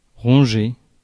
Ääntäminen
US : IPA : [nɔ]